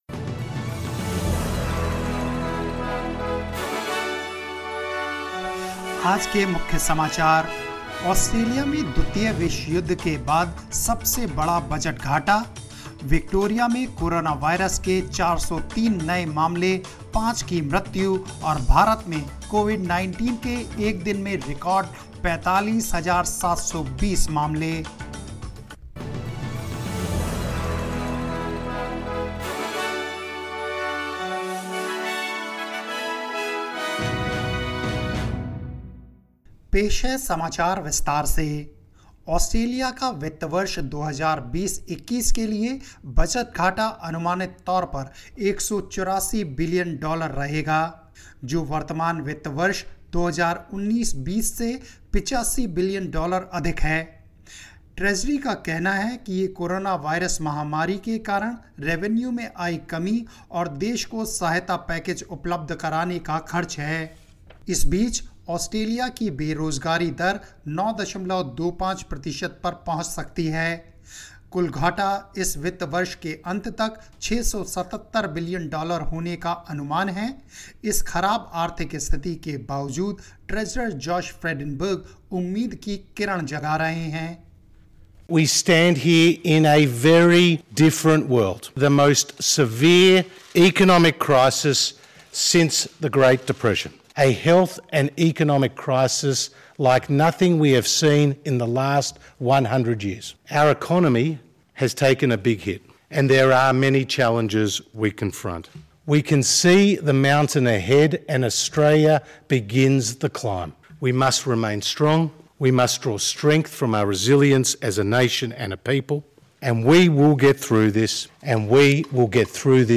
News in Hindi 23 July 2020